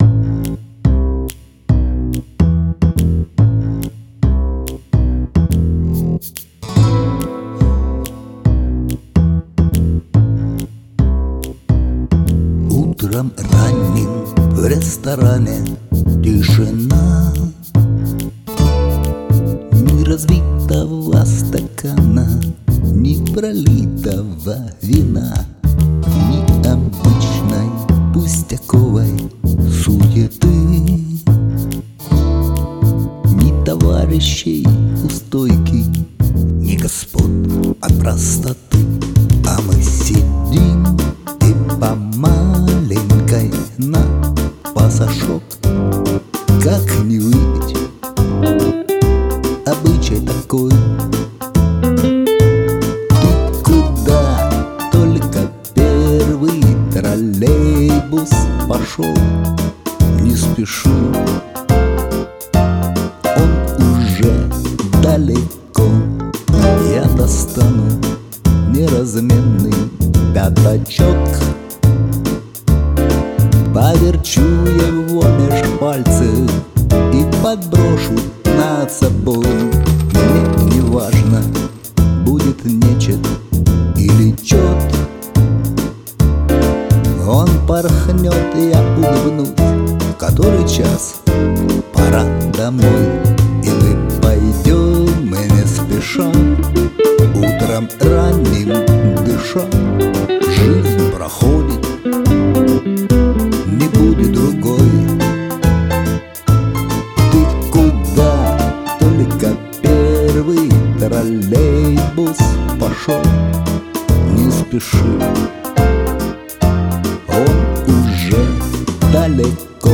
Спето с душой, сдержанным юмором.